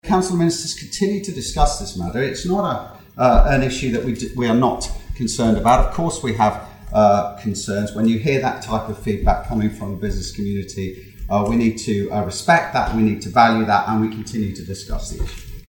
Speaking at this week's sitting of the House of Keys, Alfred Cannan insisted ministers are listening: